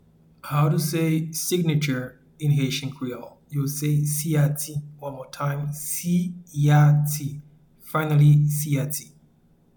Pronunciation and Transcript:
Signature-in-Haitian-Creole-Siyati.mp3